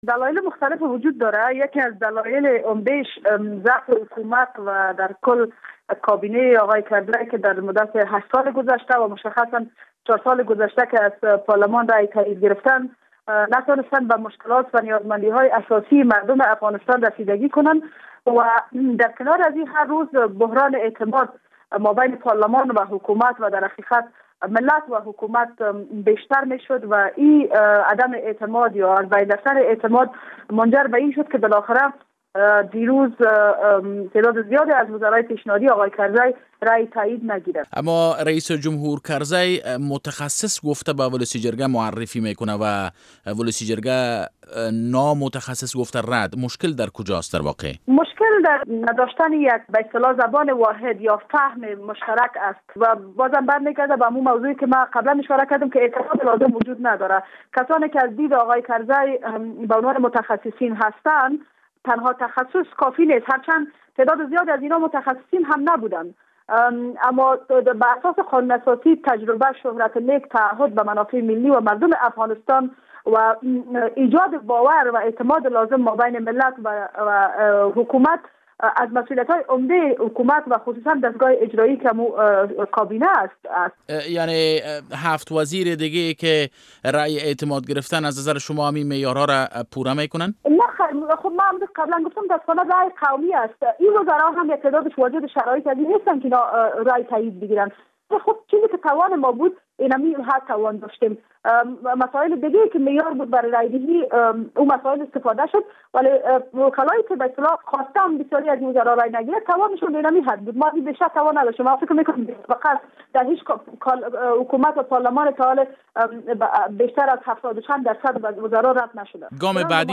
مصاحبه با فوزیه کوفی عضو ولسی جرگه افغانستان در مورد وزرای رد شده کابینه